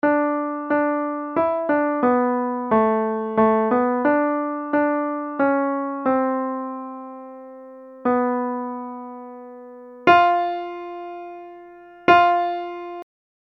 Key written in: D Major
Each recording below is single part only.
a piano